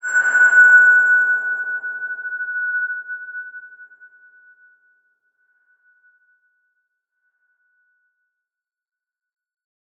X_BasicBells-F#4-ff.wav